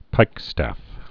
(pīkstăf)